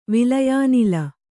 ♪ vilayānila